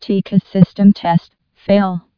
TCAS voice sound samples. ... Artificial female voice.